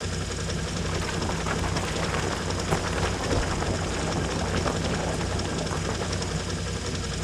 minerOperate.ogg